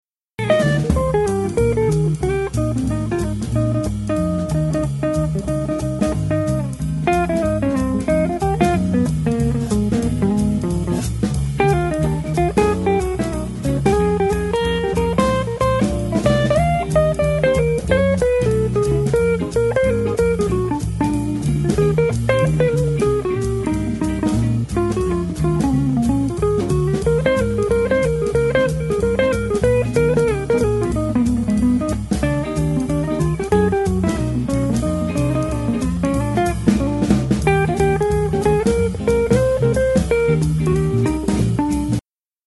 excerpt: guitar solo
jazz